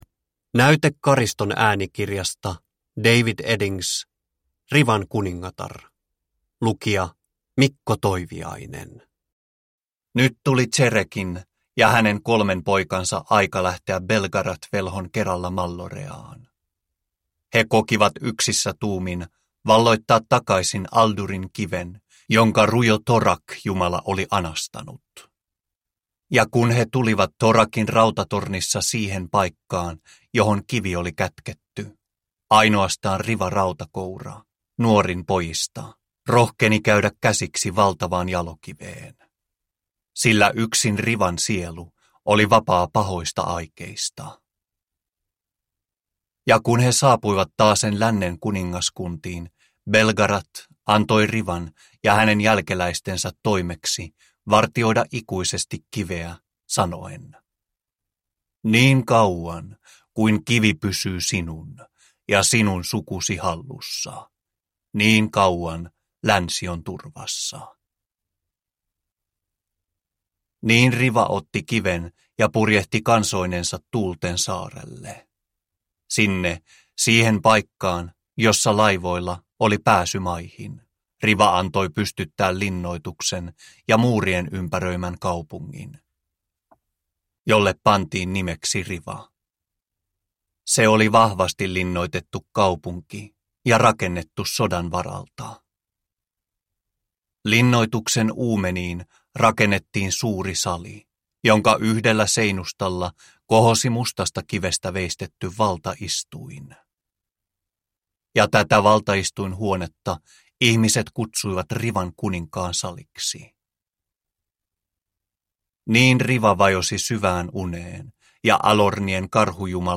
Rivan kuningatar - Belgarionin taru 4 – Ljudbok – Laddas ner